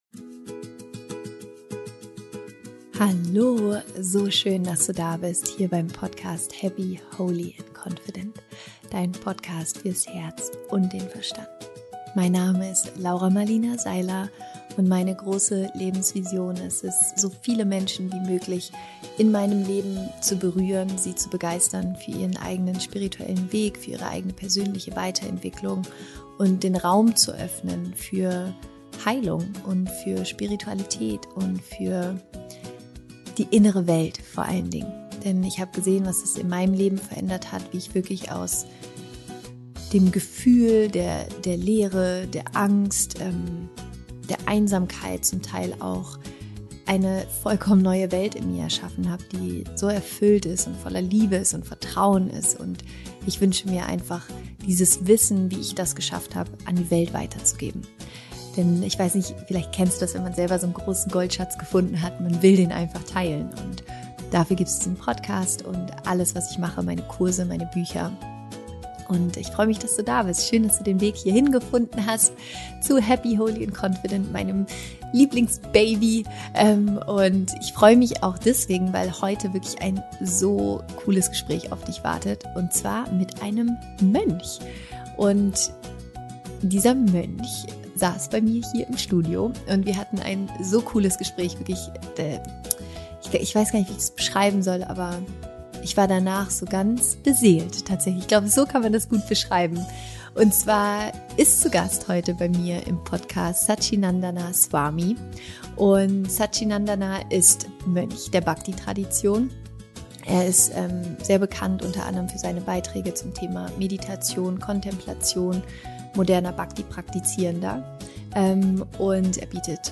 Was ist dein inneres Mantra? Interview mit einem Mönch